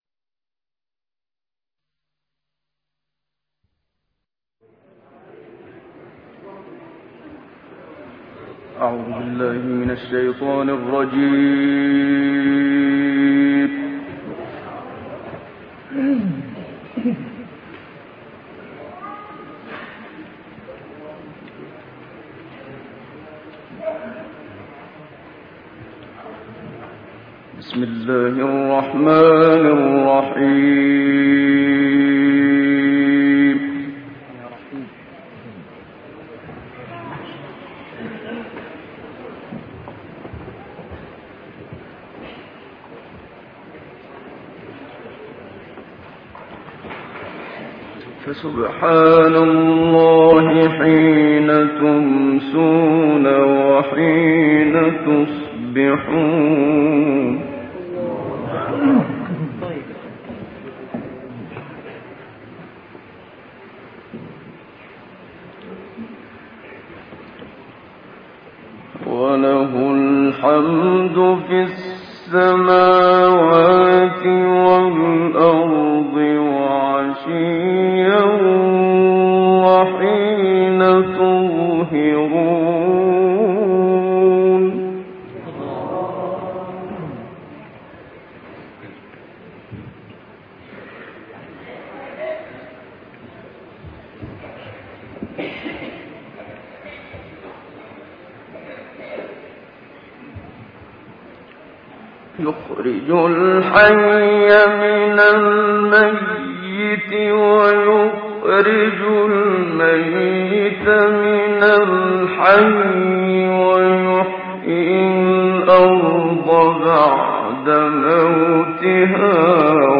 030 الروم 17-30 الضحى الشرح الفاتحة البقرة 1-5 تلاوات نادرة بصوت الشيخ محمد صديق المنشاوي - الشيخ أبو إسحاق الحويني